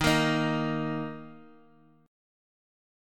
Eb5 chord